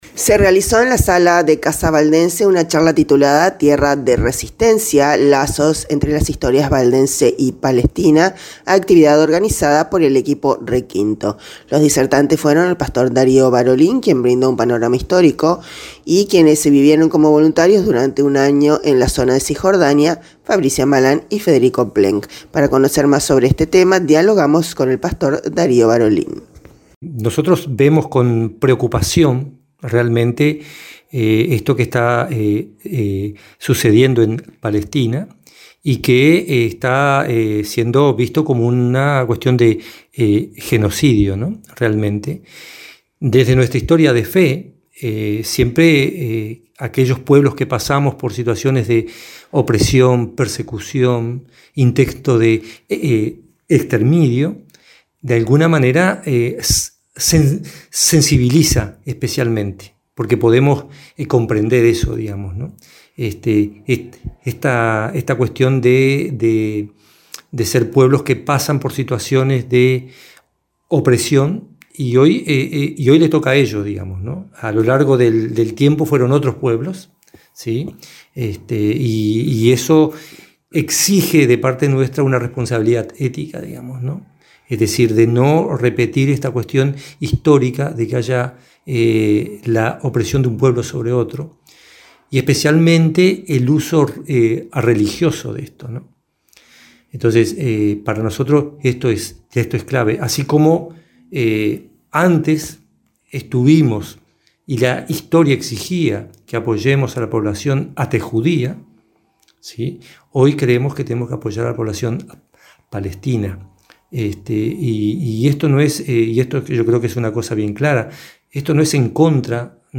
Para conocer más sobre este tema, dialogamos con el pastor